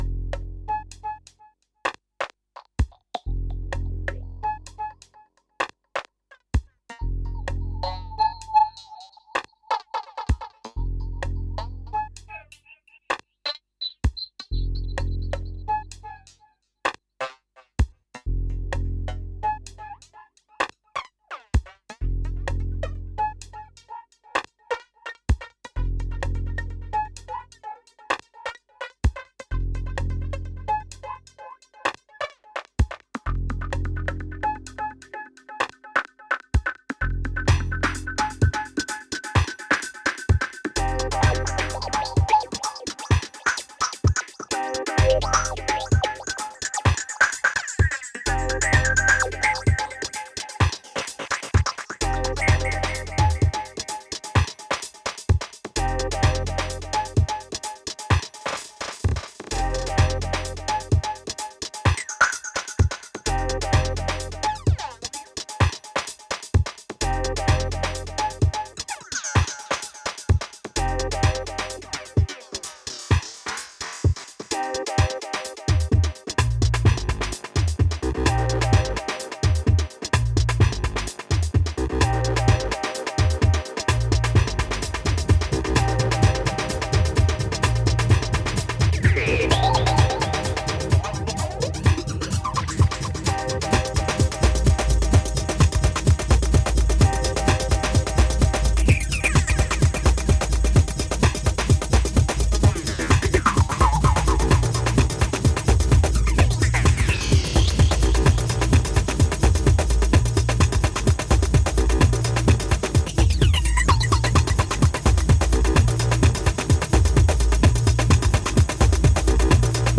Full-on raw rehearsal takes of Acid Ambient tracks.